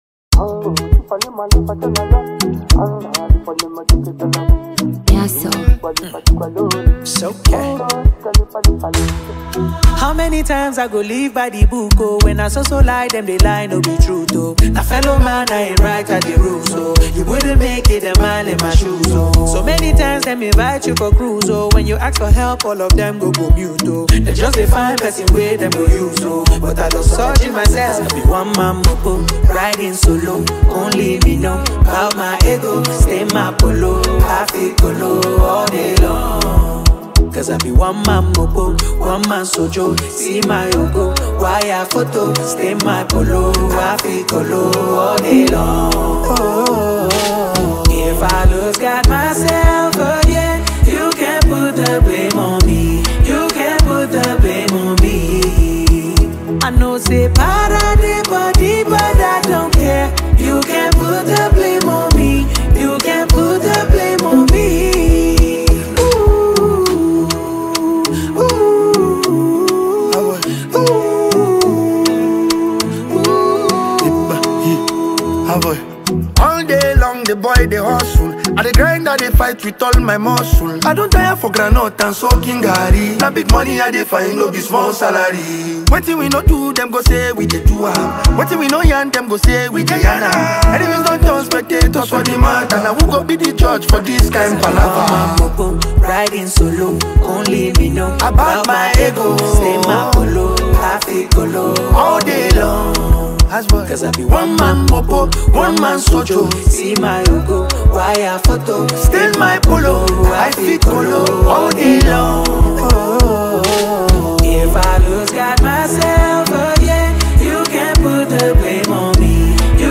good music with a groovy twist